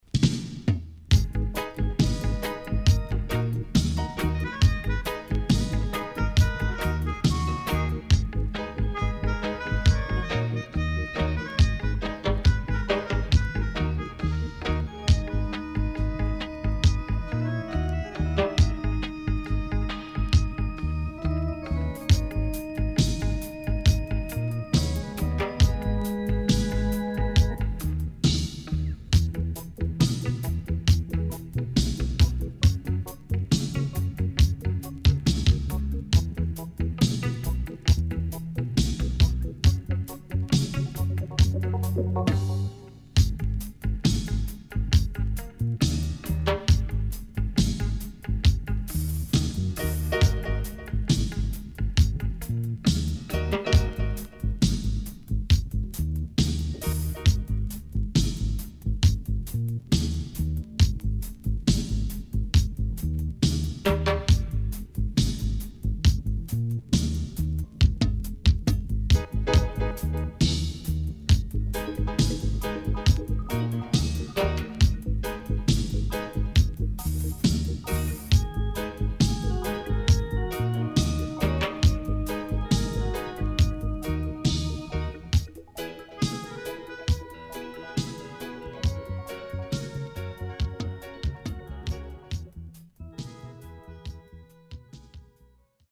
80's Killer Melodica Inst.Good Condition
SIDE A:少しノイズ入りますが良好です。